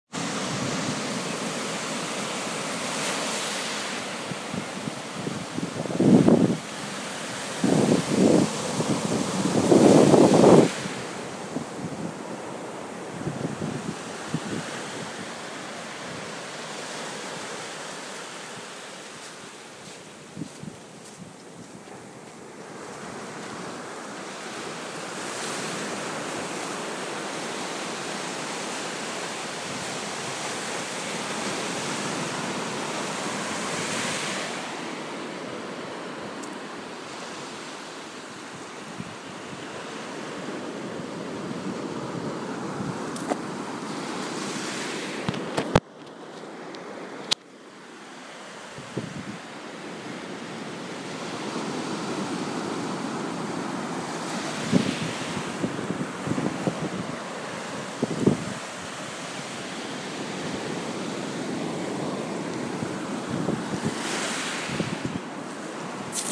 Between Runton and Cromer…